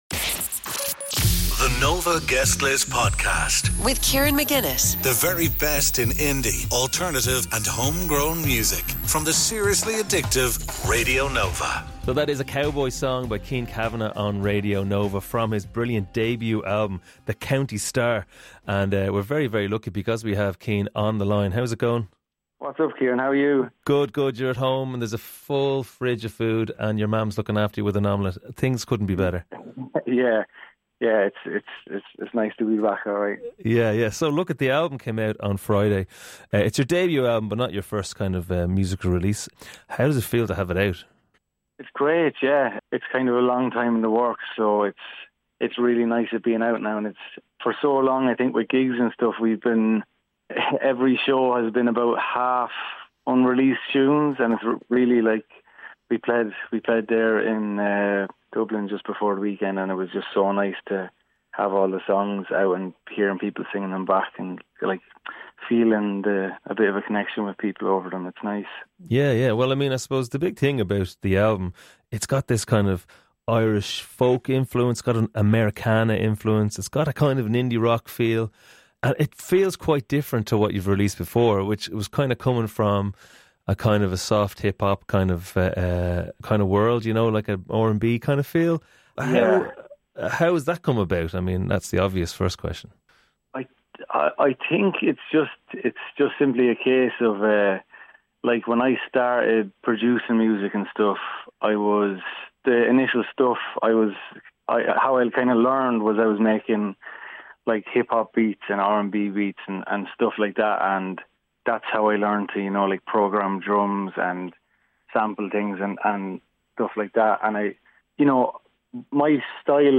The podcast brings unique interviews